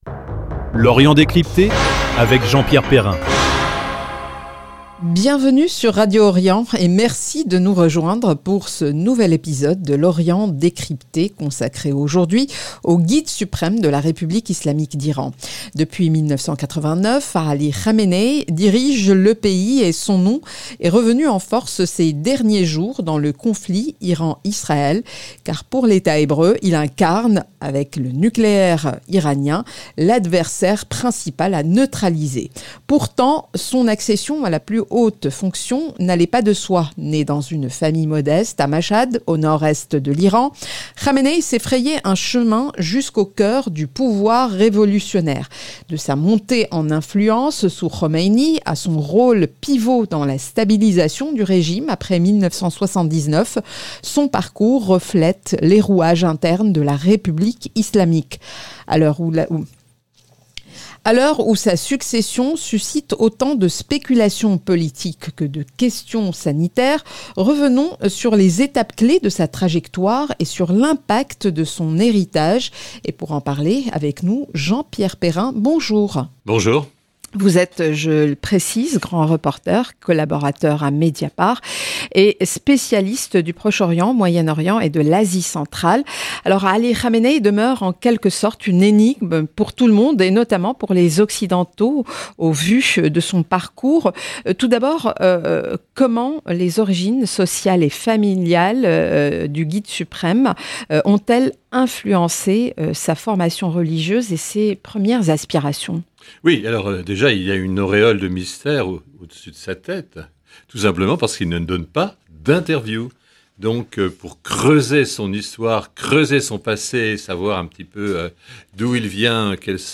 Un épisode pour comprendre l’homme, son pouvoir, et l’avenir du régime qu’il incarne. Avec le journaliste et écrivain